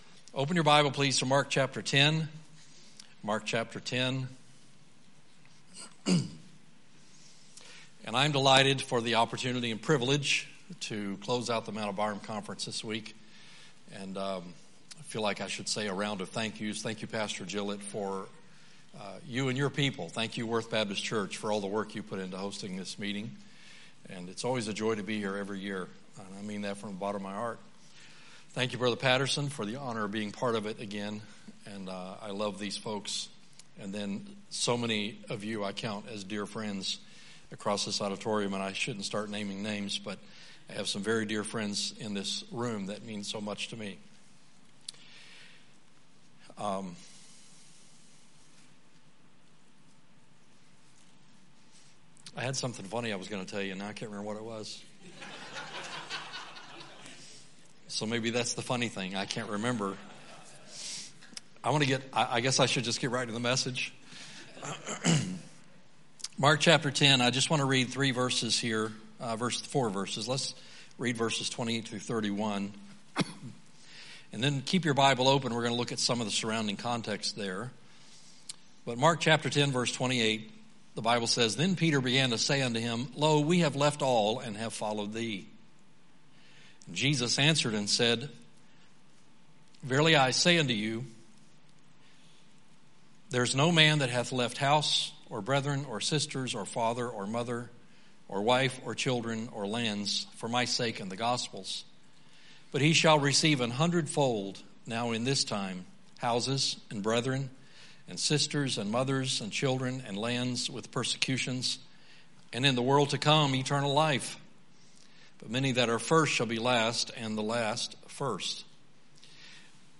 Thursday Evening
Sermons